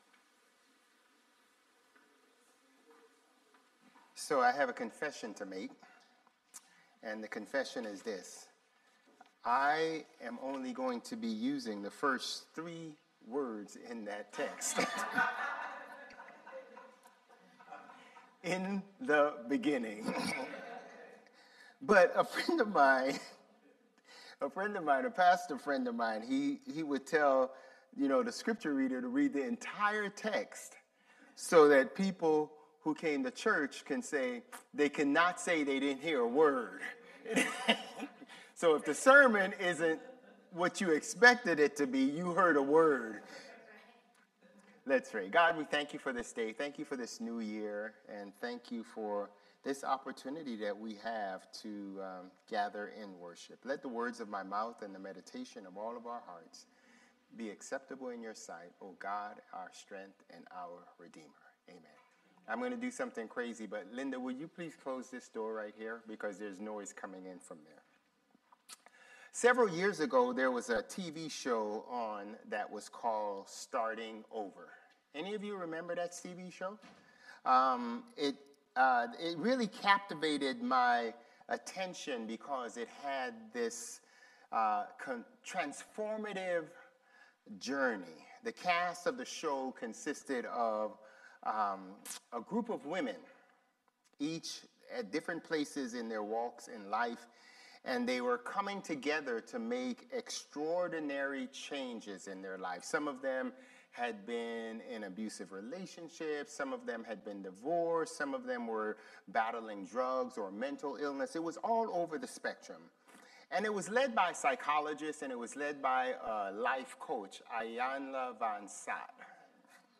Sermons | Bethel Lutheran Church
January 4 Worship